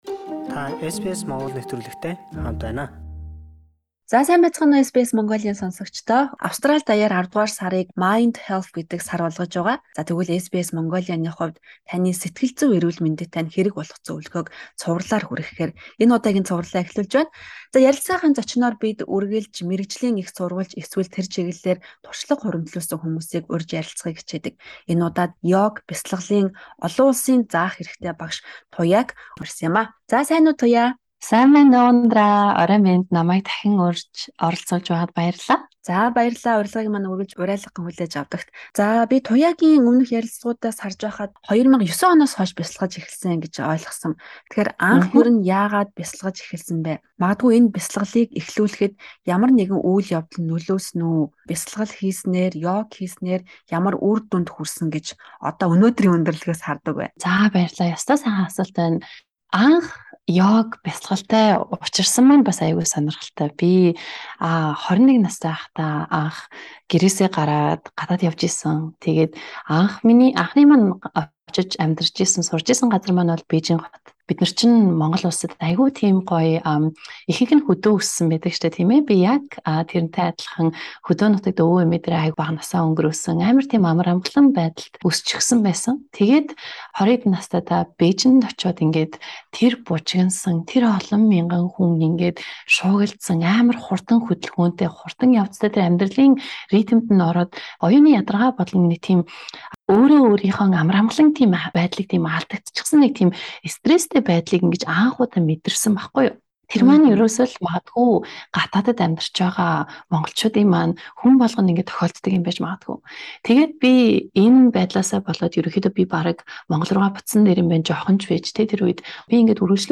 SBS Mongolian